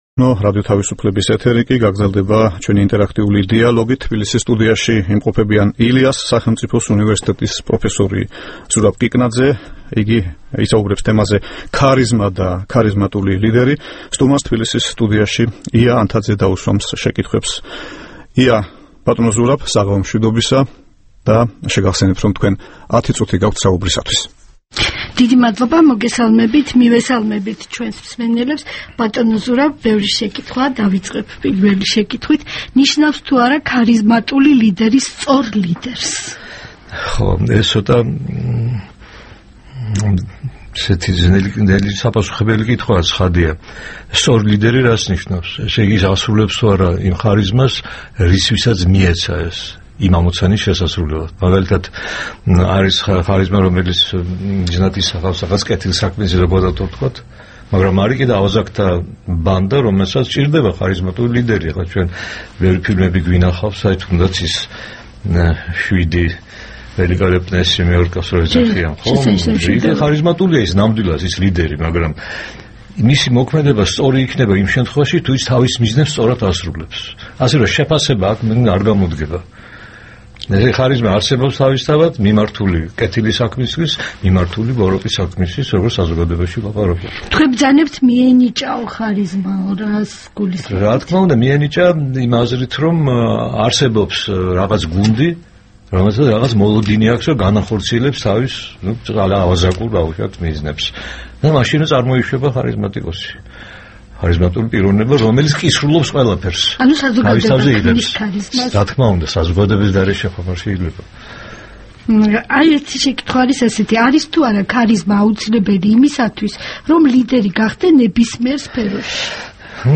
დღეს „ინტერაქტიული დიალოგის“ სტუმარია ილიას სახელმწიფო უნივერსიტეტის პროფესორი ზურაბ კიკნაძე.